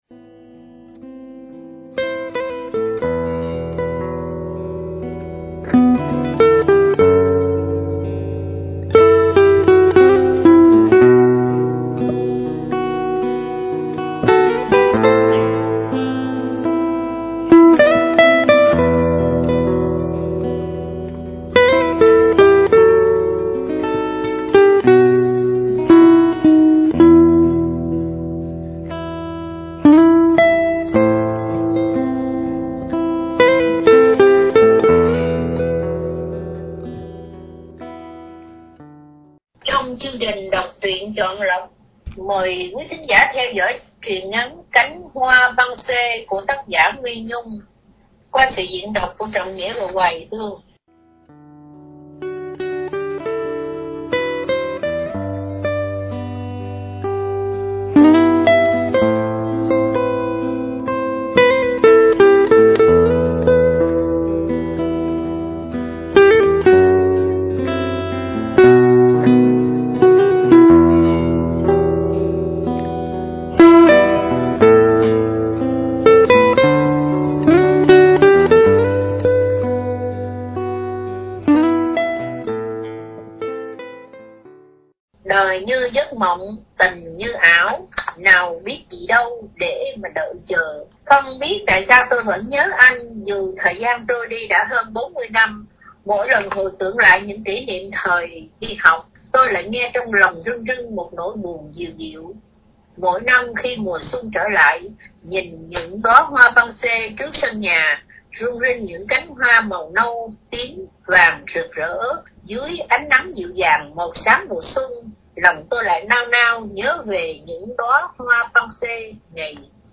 Đọc Truyện Chọn Lọc – Truyện Ngắn ” Cánh Hoa Pensee” – Radio Tiếng Nước Tôi San Diego